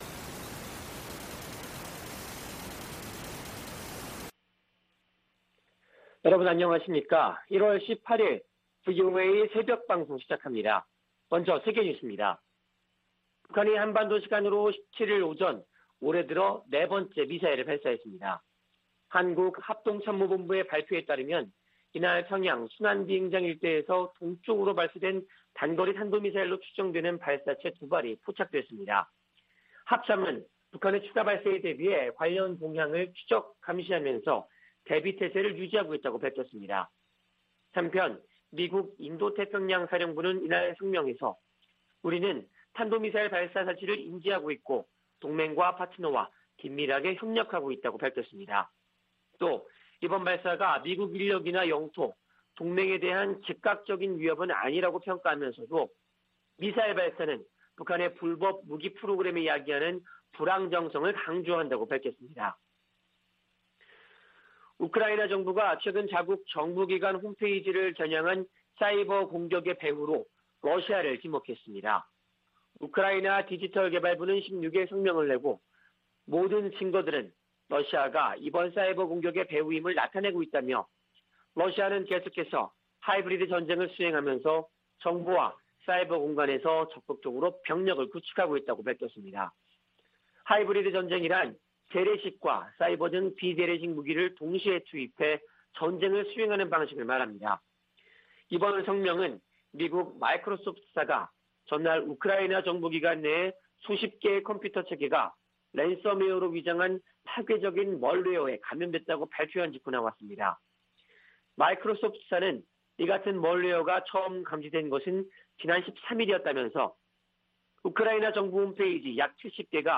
VOA 한국어 '출발 뉴스 쇼', 2021년 1월 18일 방송입니다.